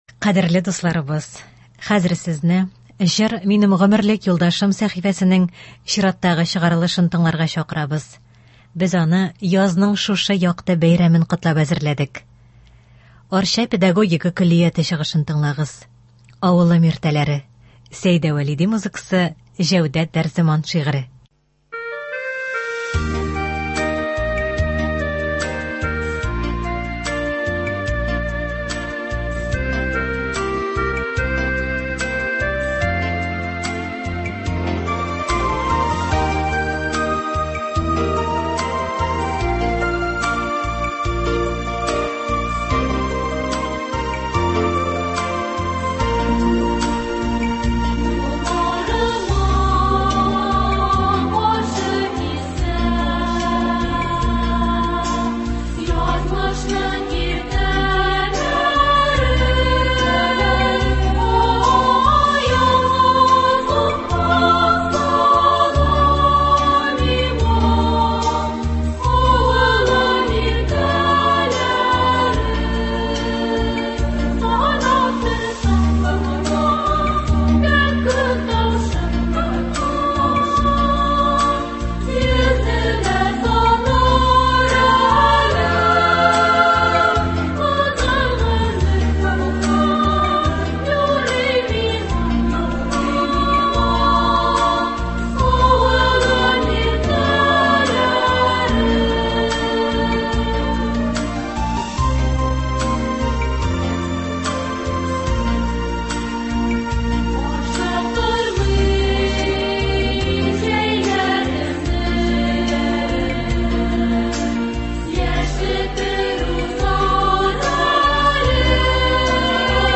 Концерт (01.05.23)